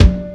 7 Foyer Snare.wav